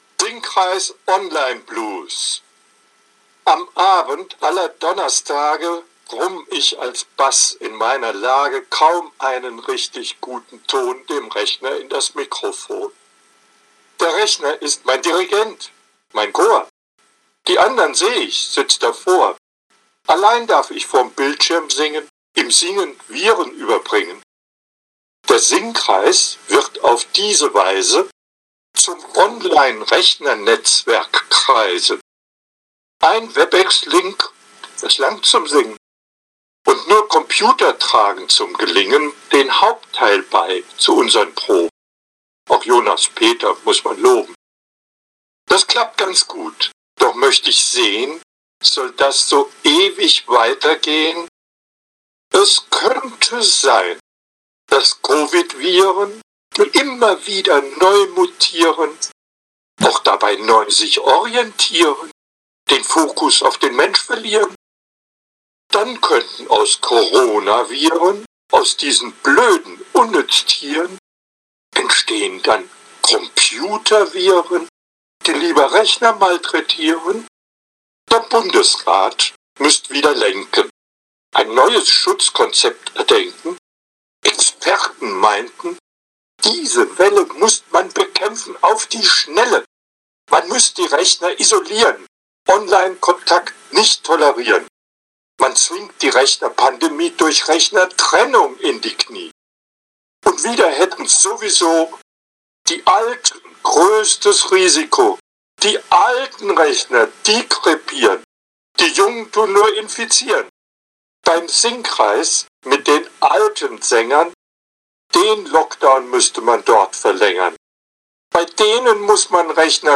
Wir sind rund zur Zeit rund 60 Sängerinnen und Sänger, die sich am Montag Abend in der Aula des Klosters Wettingen treffen, um anspruchsvolle Chormusik zu erarbeiten.